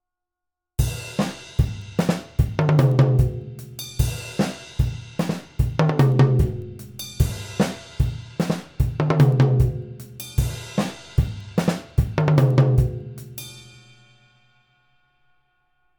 We could add two single hit to follow